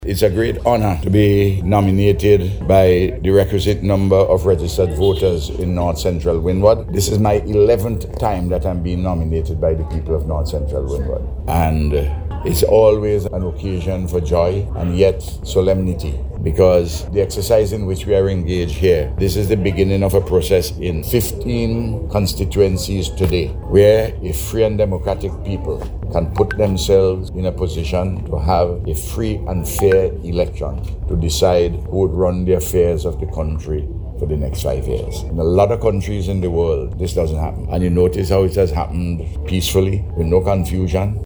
Speaking to NBC Radio at the Georgetown Police Station following the filing of his nomination papers, Prime Minister Gonsalves said he is honoured to be nominated by the people of North Central Windward.